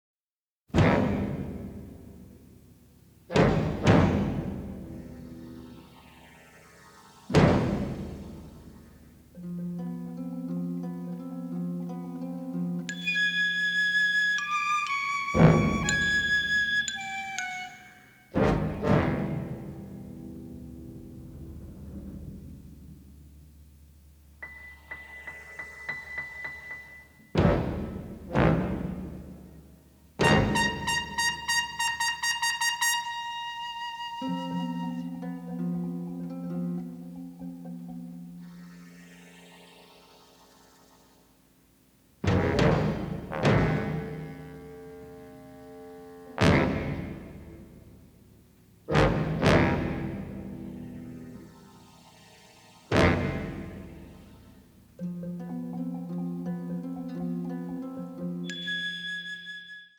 Sound quality is excellent.